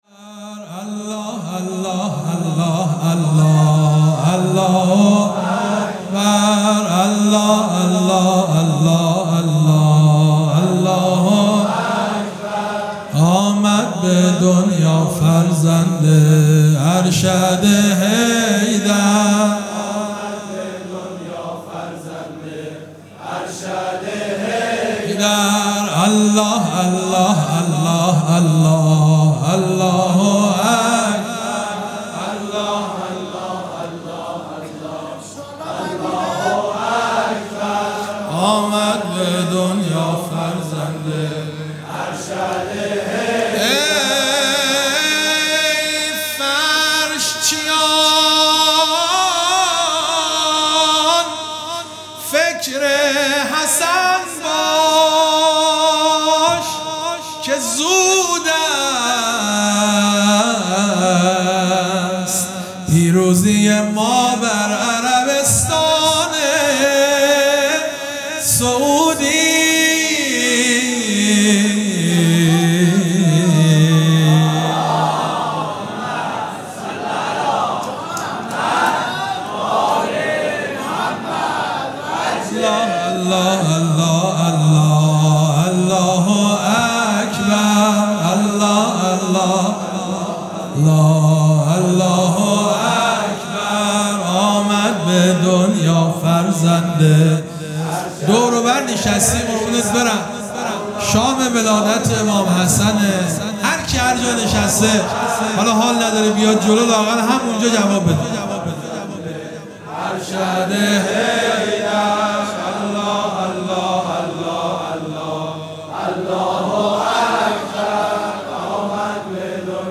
سرود
مسجد جامع یزد